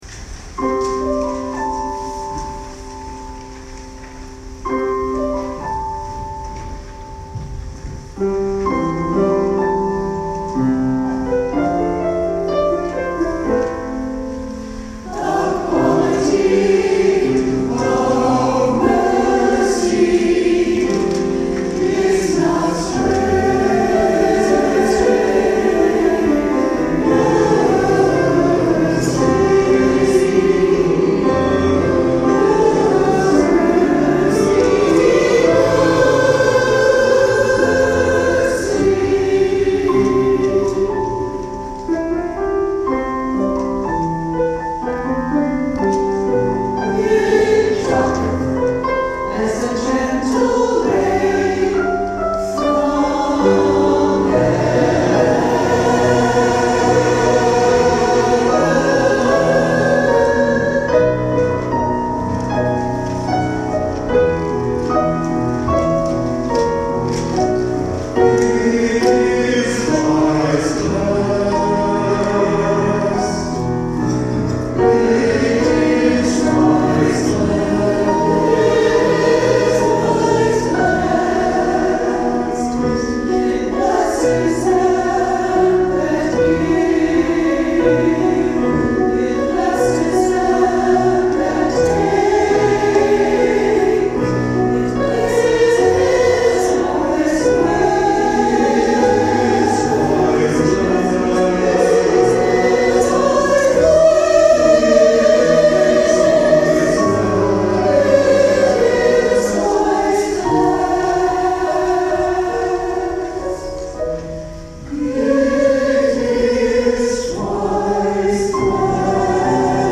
"War is Kind" (SATB a capella)
Commissioned for Eight By Six choral concert (2020)